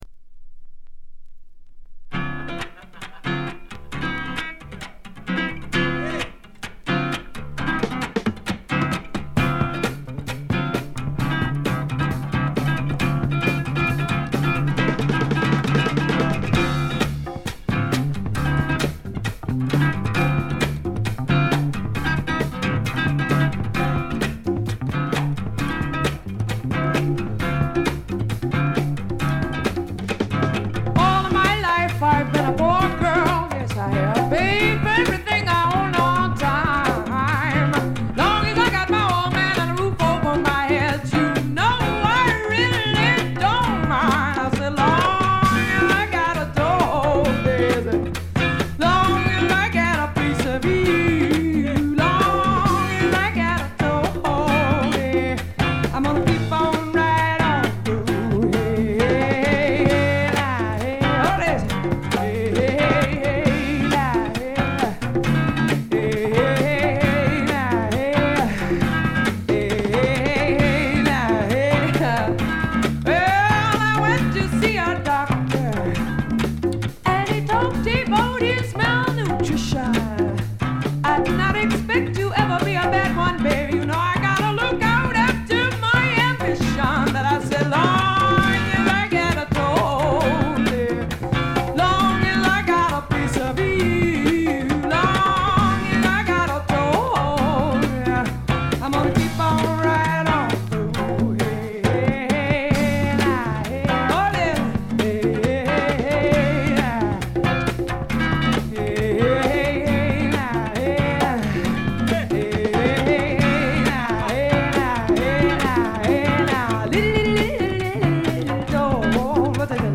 部分試聴ですがほとんどノイズ感無し。
試聴曲は現品からの取り込み音源です。
Side A Recorded Live at The Bitter End, N.Y.C.